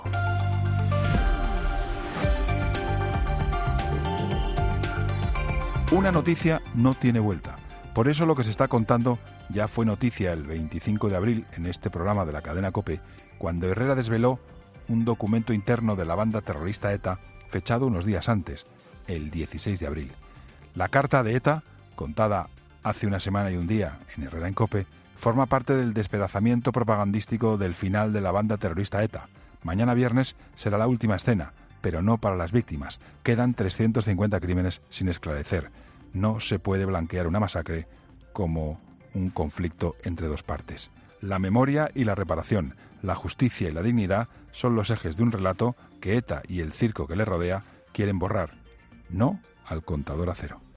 AUDIO: Escucha el comentario de Juan Pablo Colmenarejo en 'Herrera en COPE' sobre la disolución de ETA